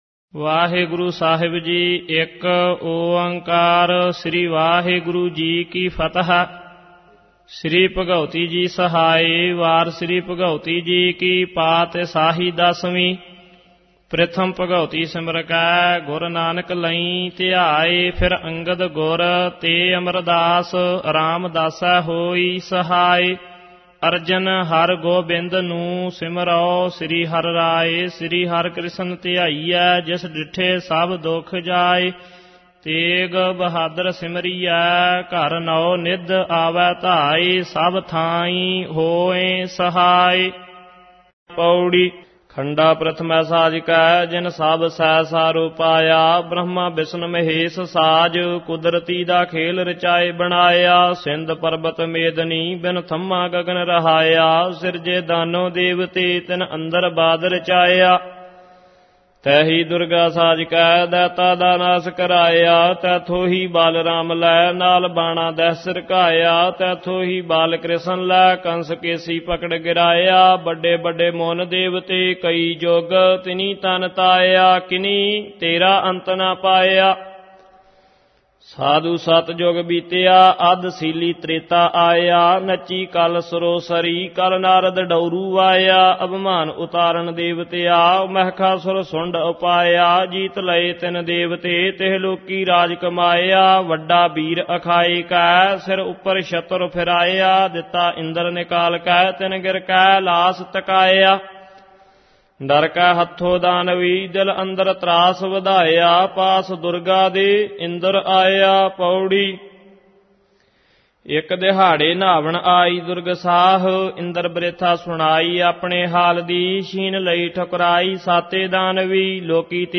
Download and Listen -Gurbani Ucharan(Paath Sahib
Album:Chandi.Di.Vaar Genre: -Gurbani Ucharan Album Info